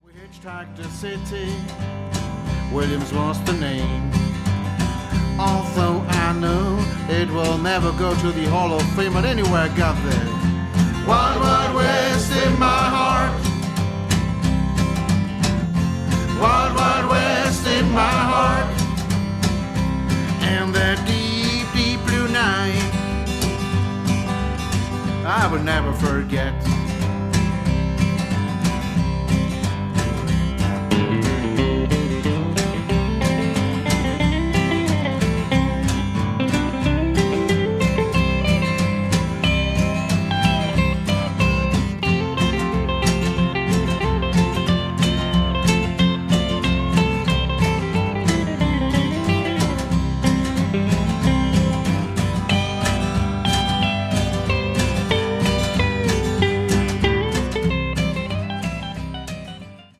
Kontrabassist
Gitarre und Gesang
Mundharmonika
an der Orgel und dem Piano
am Schlagzeug